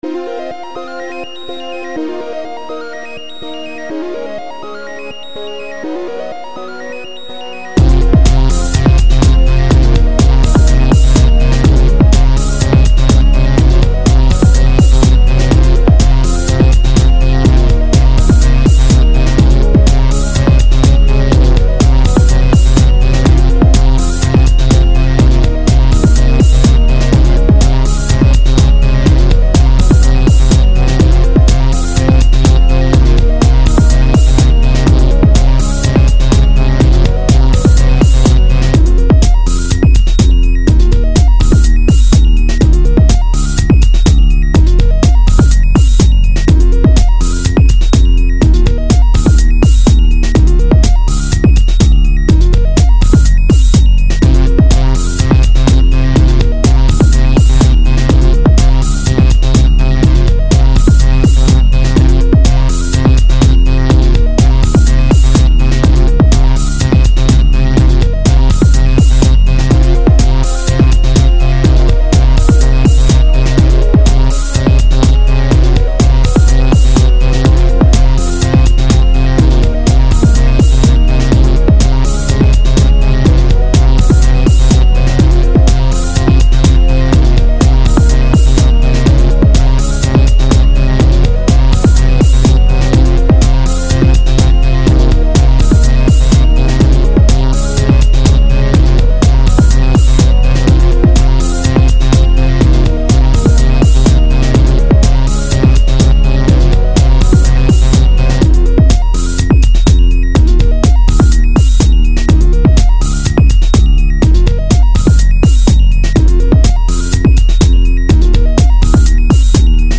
instumental
エレクトロ風インストビーツ。